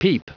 Prononciation du mot peep en anglais (fichier audio)
Prononciation du mot : peep